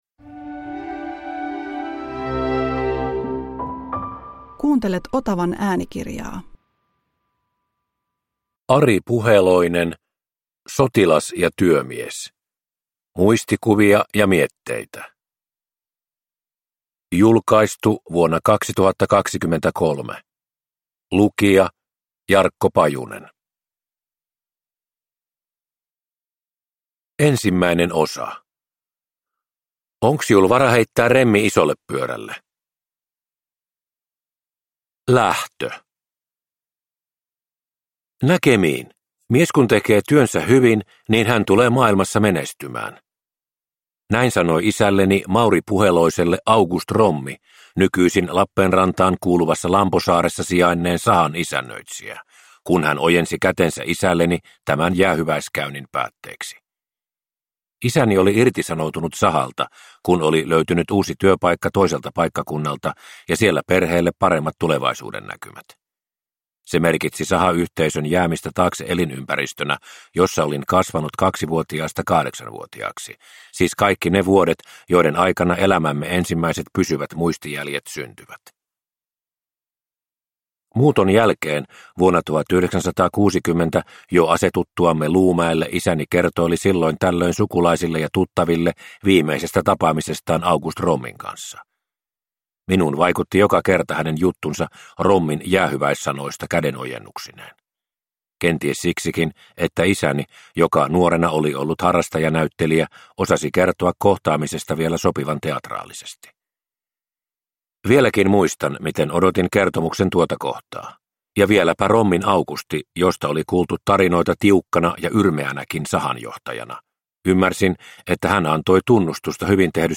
Sotilas ja työmies – Ljudbok – Laddas ner